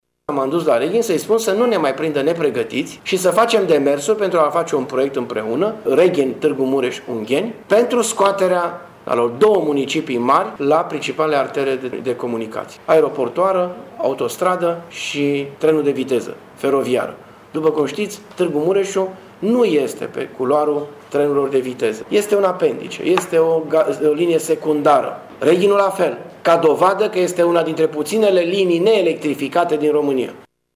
Practic, este vorba de un proiect prin care cele doua municipii să fie scoase din anonimat, spune primarul Dorin Florea.